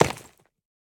Minecraft Version Minecraft Version 1.21.5 Latest Release | Latest Snapshot 1.21.5 / assets / minecraft / sounds / block / pointed_dripstone / land1.ogg Compare With Compare With Latest Release | Latest Snapshot